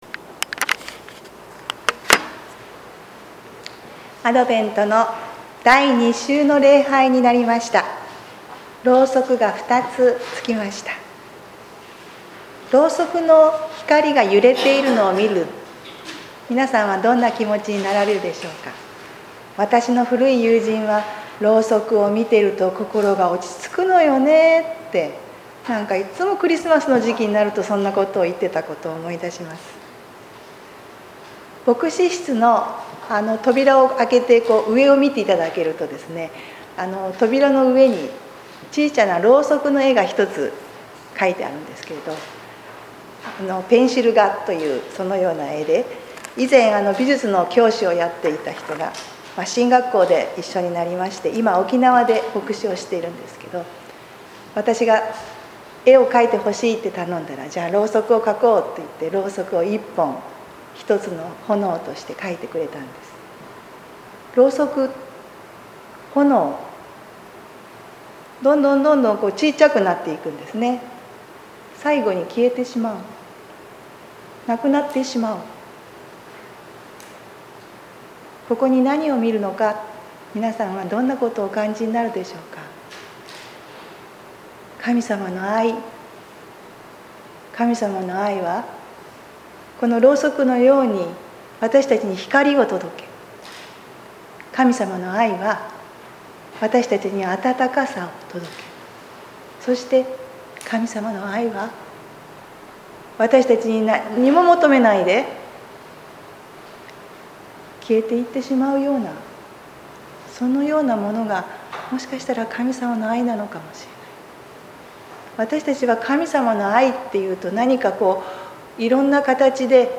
2021年12月5日 アドベント第二週礼拝 見失った羊